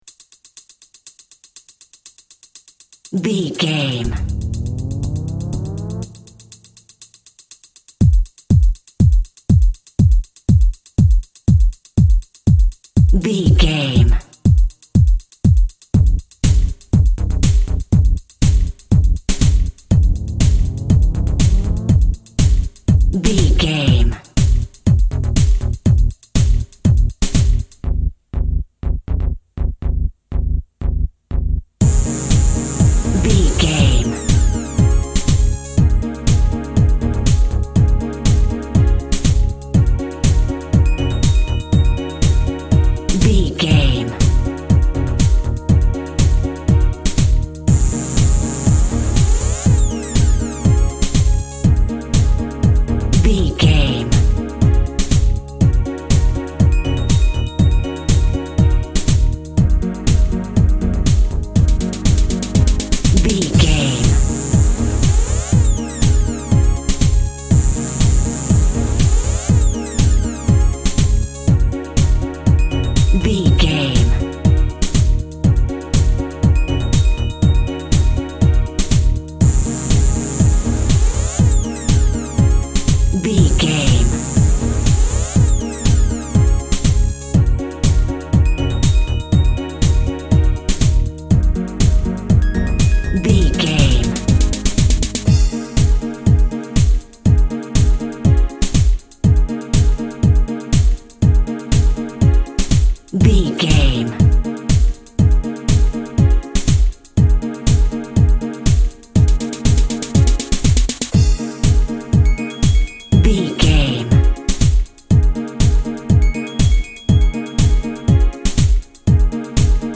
Aeolian/Minor
B♭
groovy
futuristic
uplifting
strings
synthesiser
drum machine
techno
synth lead
synth bass
Synth Pads